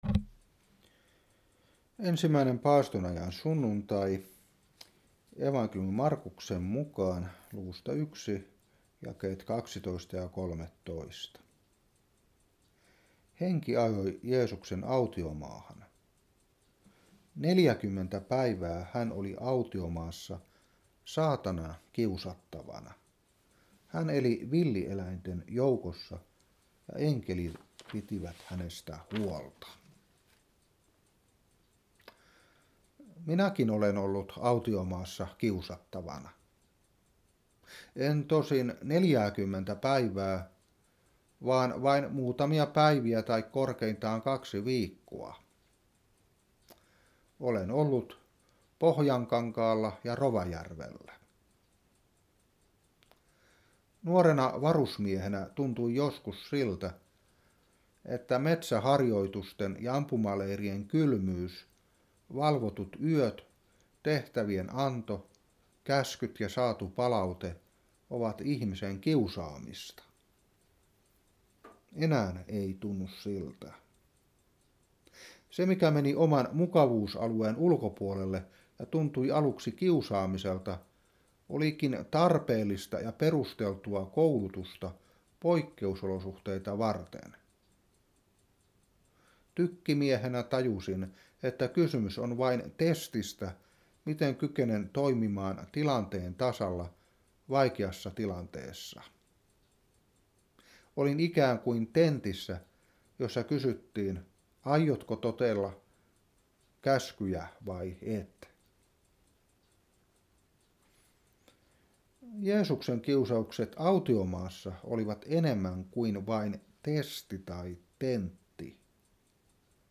Saarna 2018-2. Mark.1:12-13.